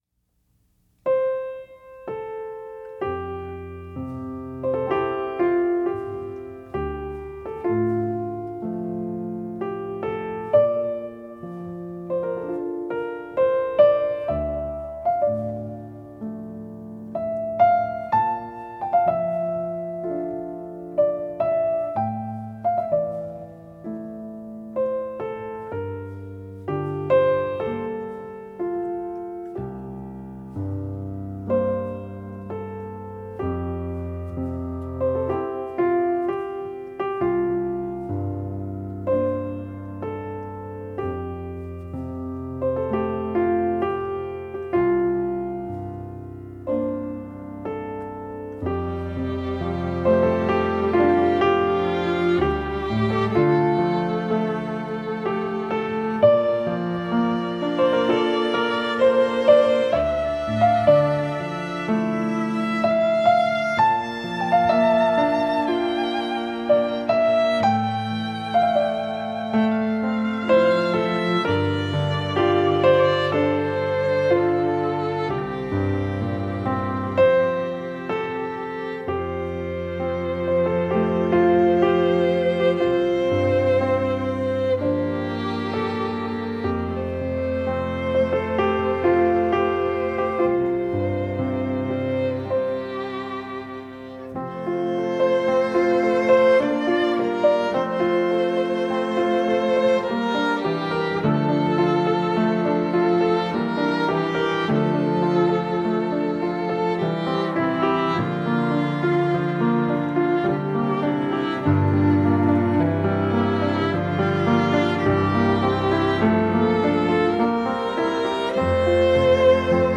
Musique originale